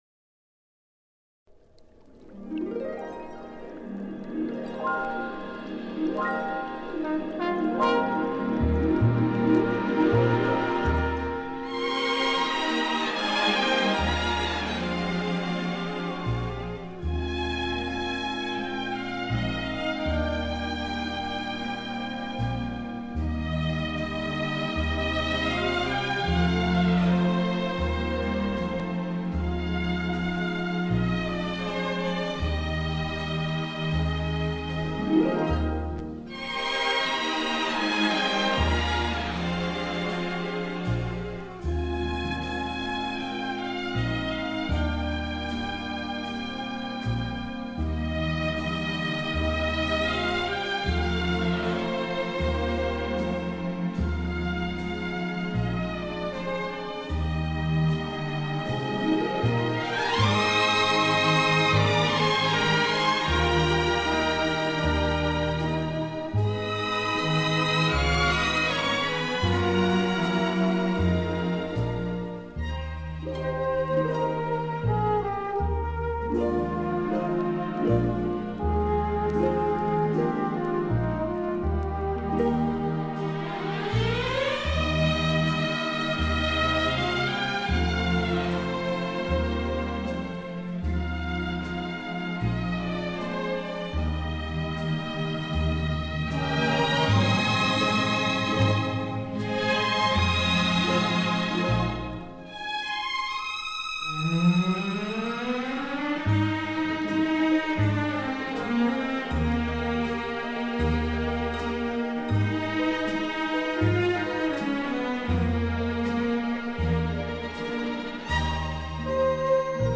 Genre:World Music